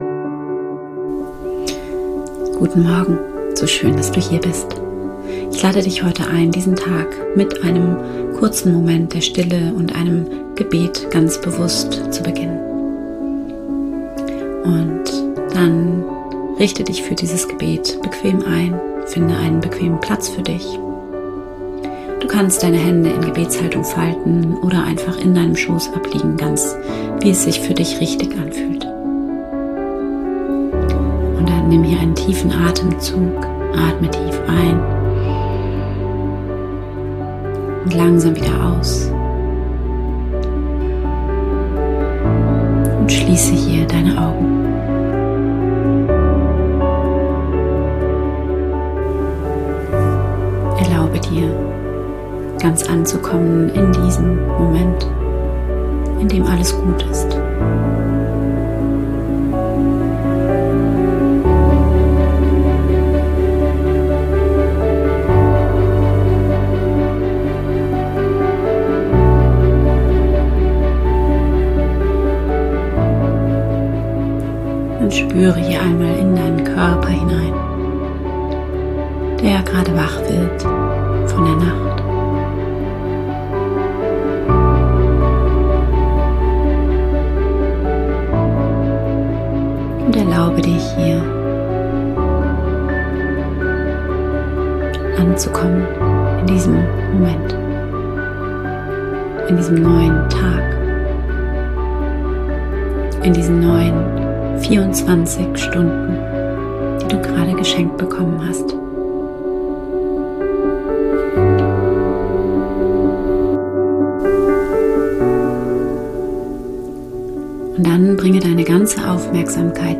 Guten-Morgen-Gebet